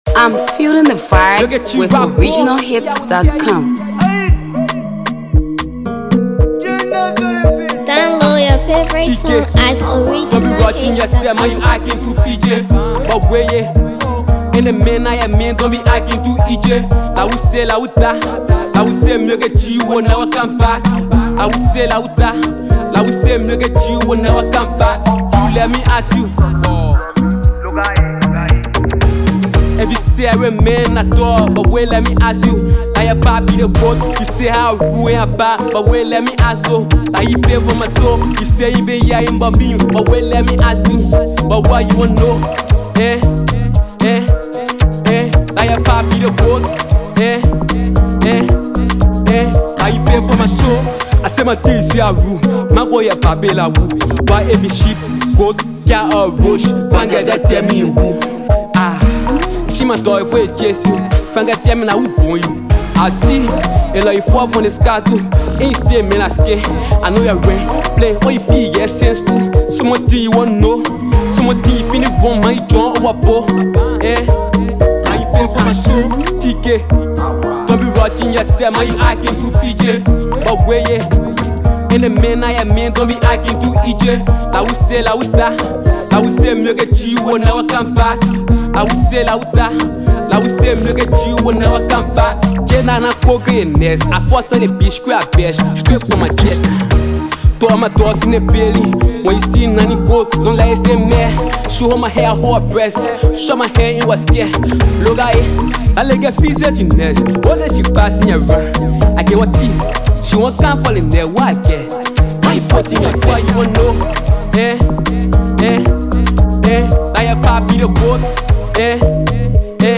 Talented Liberian uprising music star
studio effort